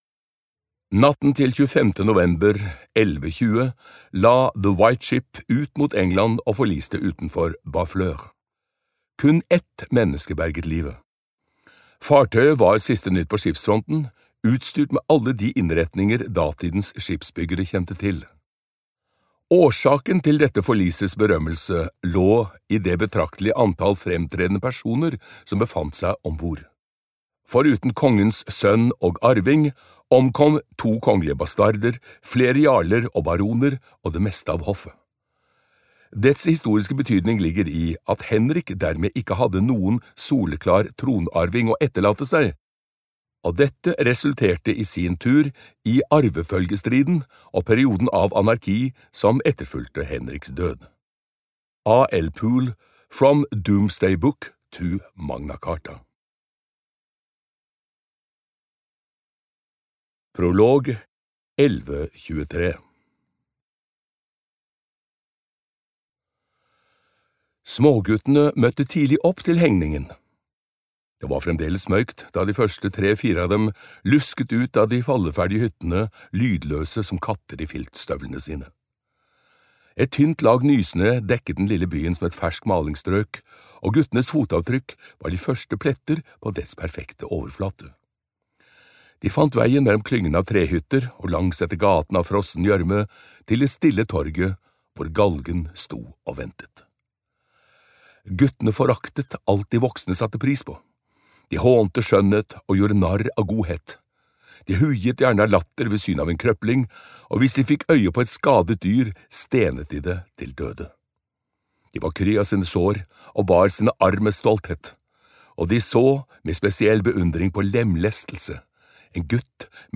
Stormenes tid - Del 1 (lydbok) av Ken Follett